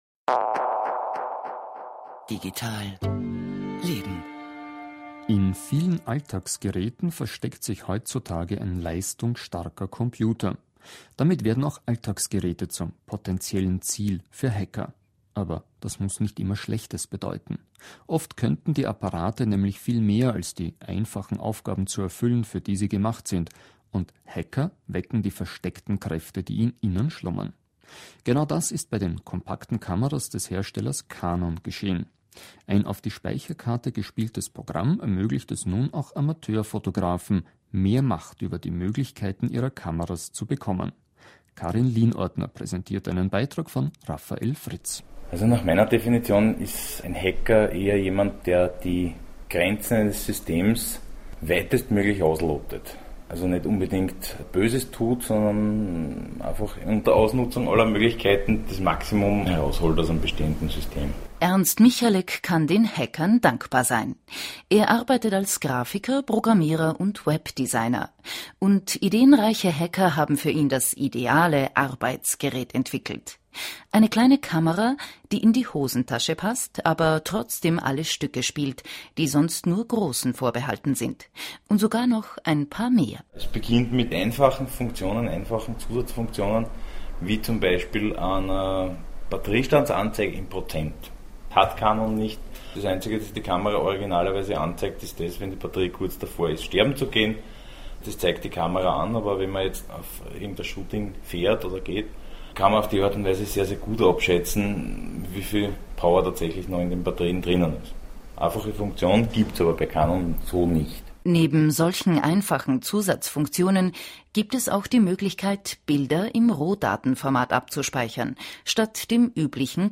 Wieder da – und im Radio
Während ich weg war, konnte man Ausschnitte eines Interviews mit mir im Radiosender Ö1 hören.